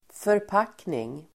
Uttal: [förp'ak:ning]